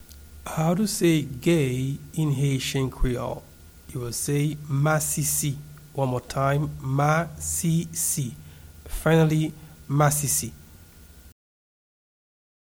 Pronunciation and Transcript:
Gay-in-Haitian-Creole-Masisi-pronunciation.mp3